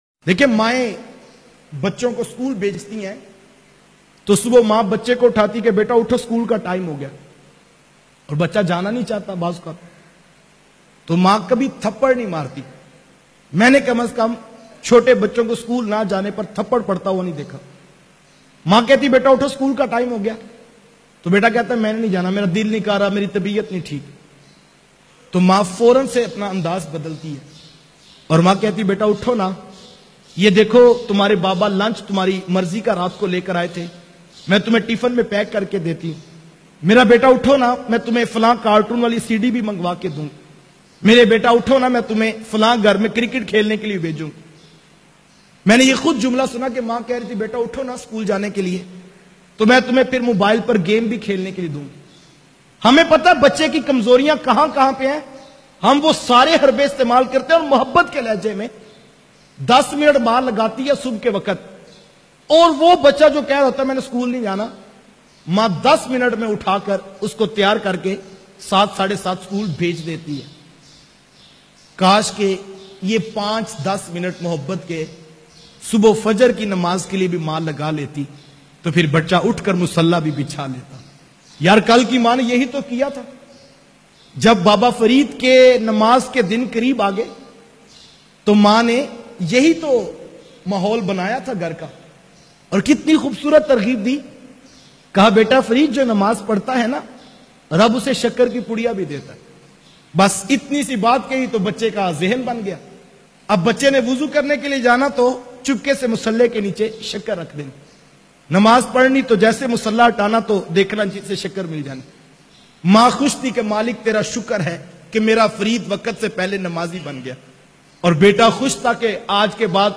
Agar Maa chahe to bayan mp3